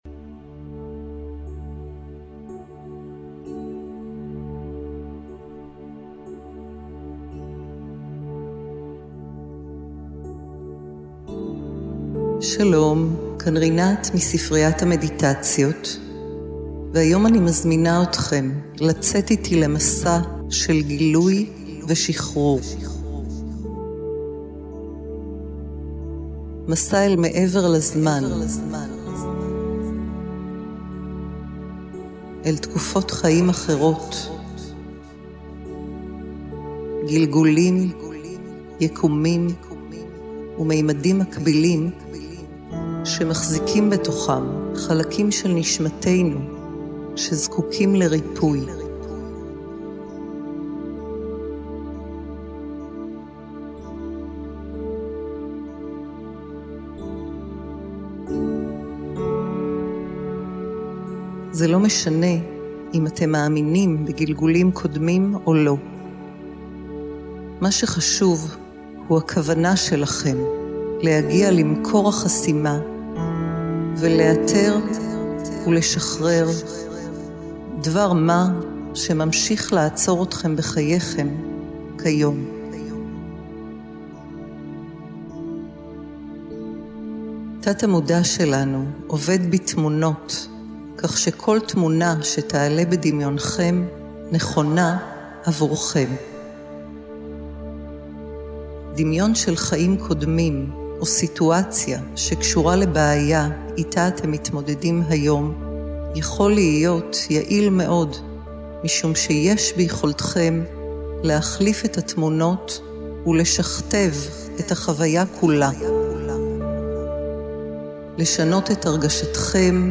דמיון מודרך באורך 23.4 דק', מלווה במוזיקה נעימה ומגיעה ב-2 גרסאות:
1. הקלטת הראשונה – המדיטציה לפתיחת חסמים משולבת במוזיקה מדיטטיבית נעימה.
2.  הקלטת השנייה – המדיטציה לפתיחת חסמים משולבת במוזיקה ועם תדרים בינוראלים ואיזוכרונים לשחזור גלגולים.
דוגמה מתוך המדיטציה לפתיחת חסמים דרך גילגולים קודמים:
קטע מתוך הדמיון מודרך לשחרור חסמים להגשמה